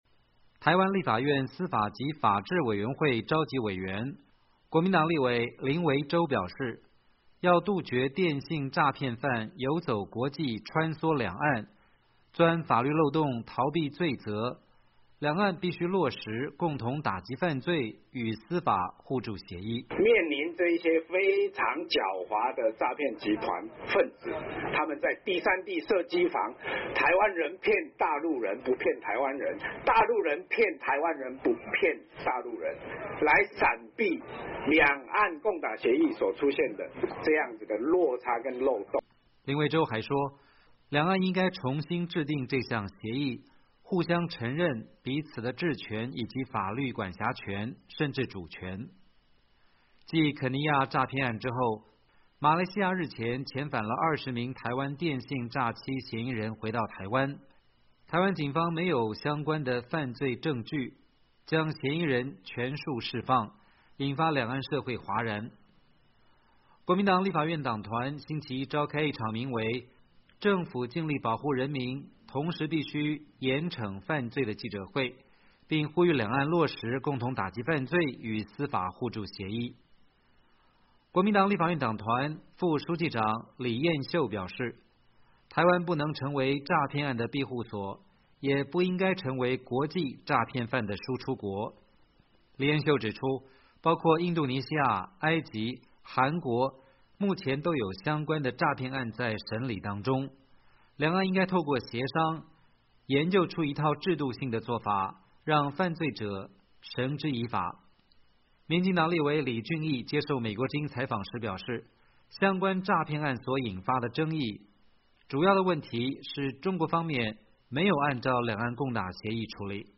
国民党立法院党团召开记者会呼吁两岸落实共打协议
民进党立委李俊俋接受美国之音采访时表示，相关诈骗案所引发的争议，主要的问题是中国方面没有按照两岸共打协议处理。